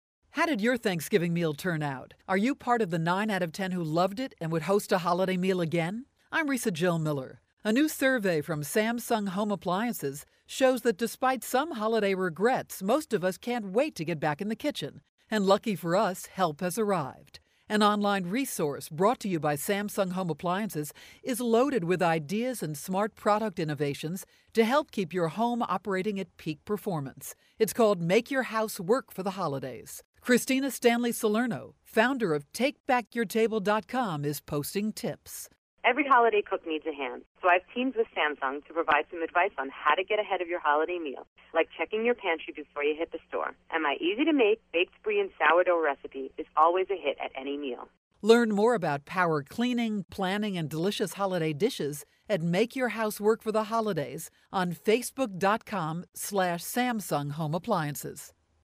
December 14, 2012Posted in: Audio News Release